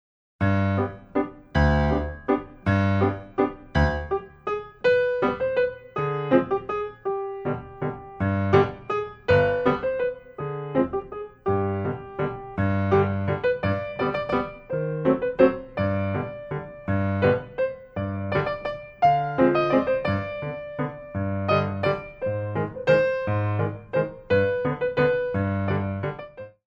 Grand Valtz
Minor Flavor